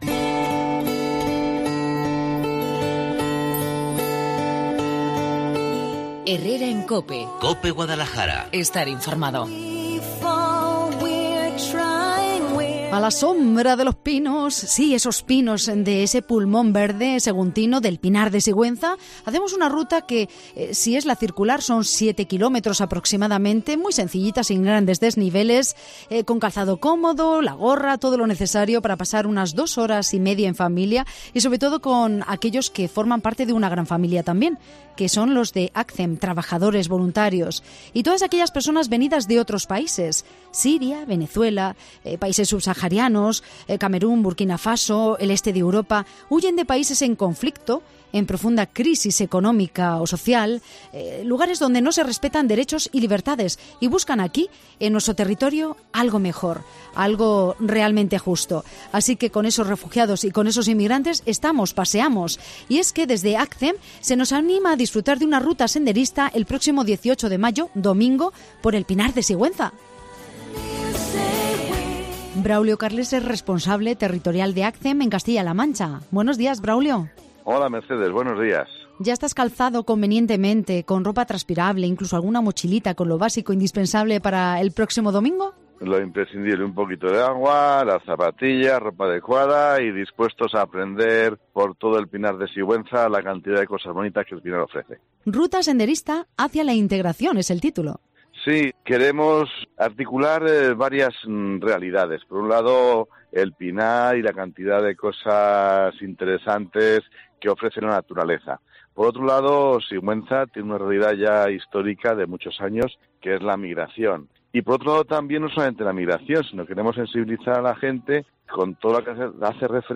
En Cope Guadalajara hemos hablado con